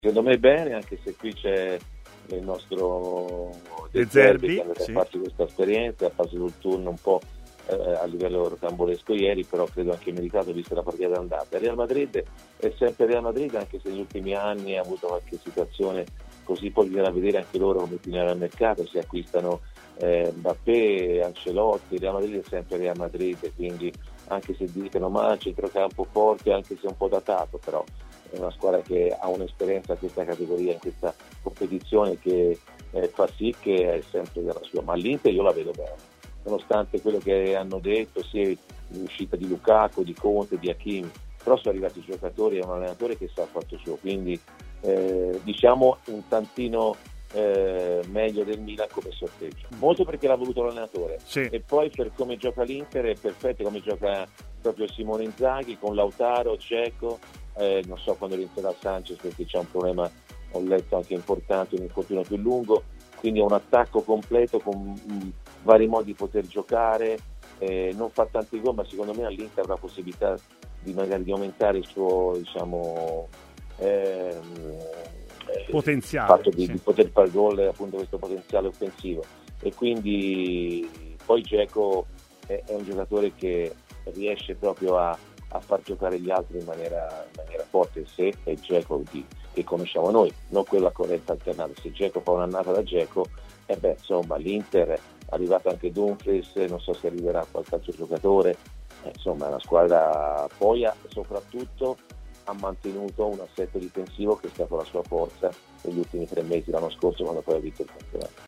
Fonte: TMW Radio
Intervenuto ai microfoni di TMW Radio, Antonio Di Gennaro ha parlato, tra gli altri temi, dell'approdo di Joaquin Correa all'Inter, alla corte di Simone Inzaghi.